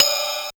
Index of /90_sSampleCDs/300 Drum Machines/Casio SA-1
Swing  Crash b Casio SA-1.wav